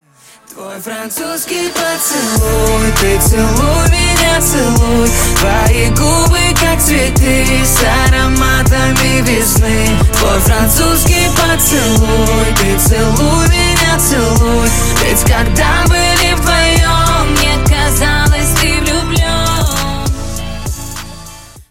• Качество: 128, Stereo
поп
дуэт